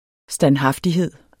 Udtale [ sdanˈhɑfdiˌheðˀ ]